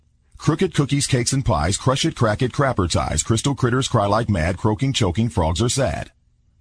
tongue_twister_03_01.mp3